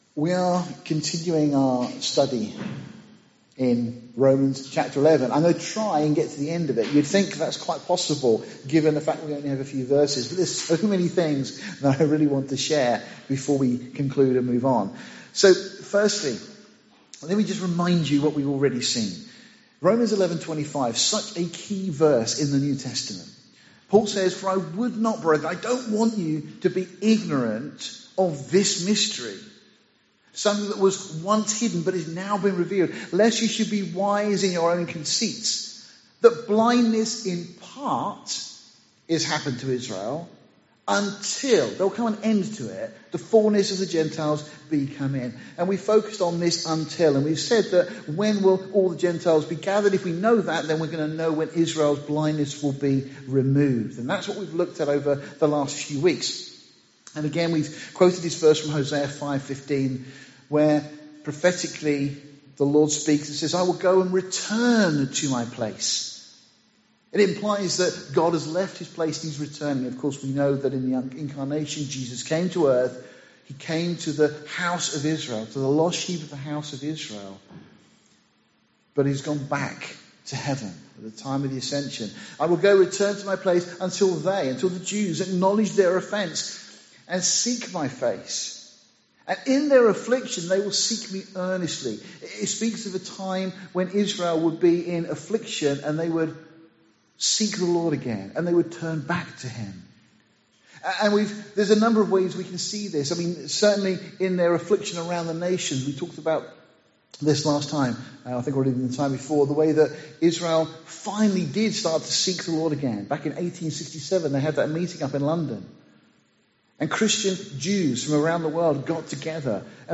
Series: Sunday morning studies Tagged with Israel , The Fulness of the Gentiles , verse by verse